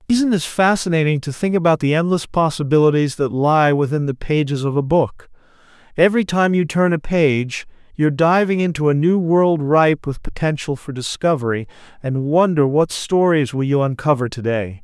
Text-to-Audio
male_normal.wav